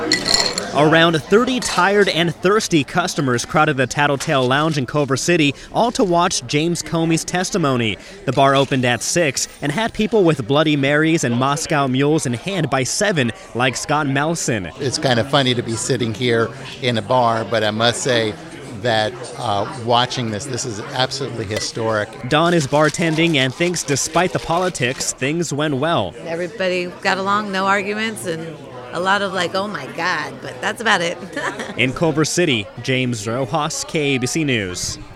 What better place to watch Comey talk about Russian meddling and the Trump administration than at the Tattle Tale Lounge in Culver City?